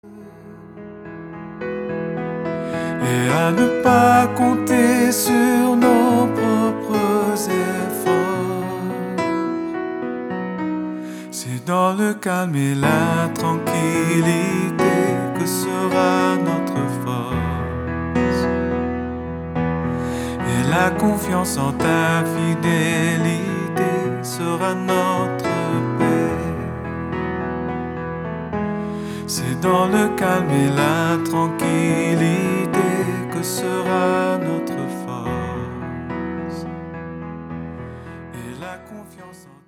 allant du pop au rock en passant par des ballades douces